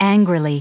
Prononciation du mot angrily en anglais (fichier audio)
Prononciation du mot : angrily